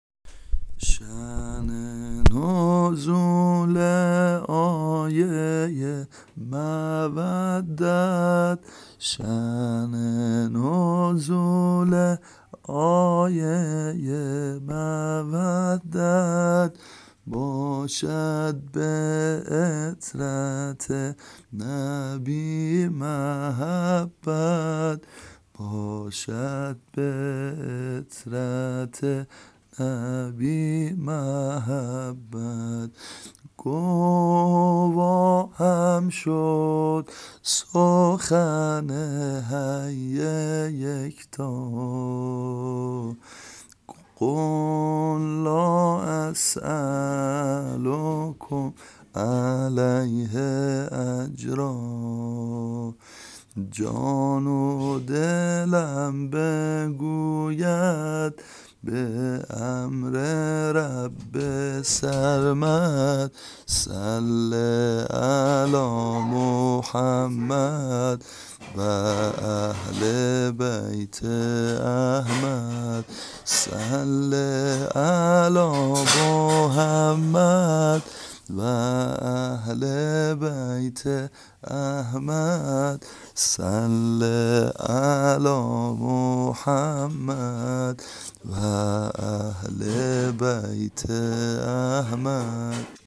سرود